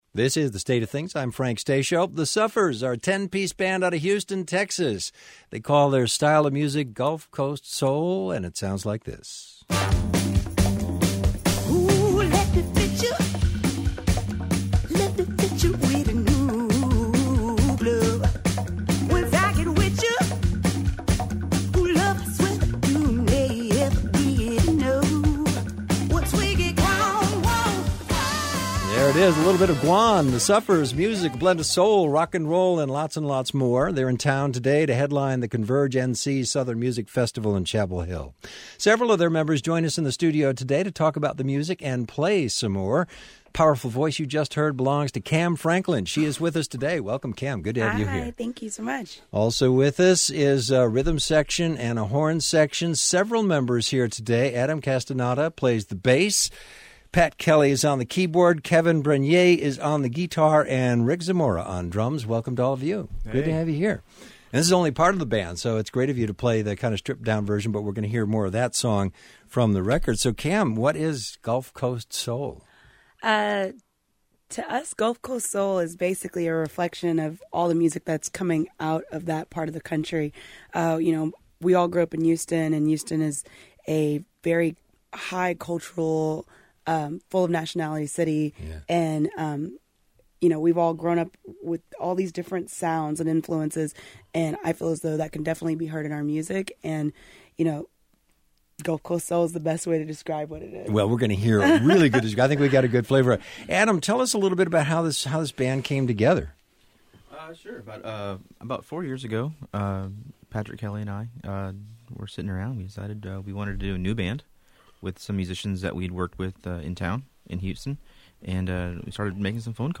vocals
keyboard
guitar
bass
percussion